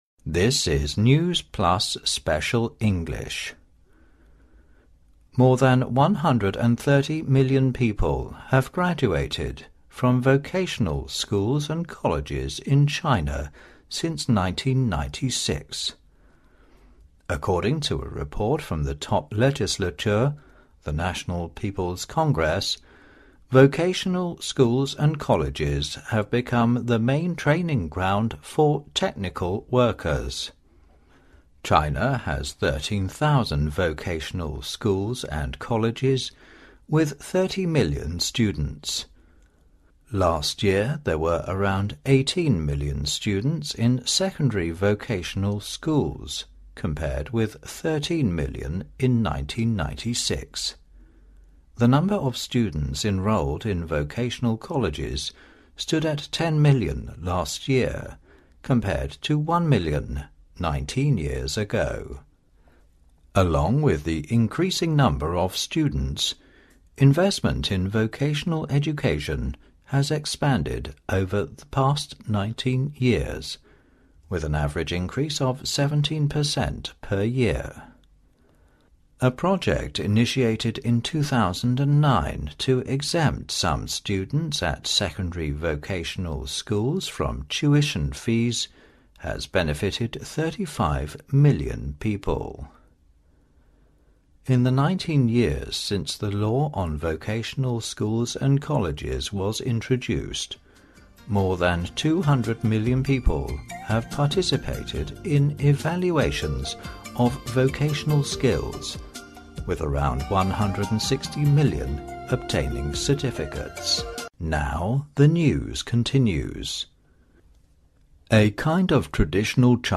News Plus慢速英语:全国人大检查职业教育法实施报告 抗癌中药通过美国FDA审核调查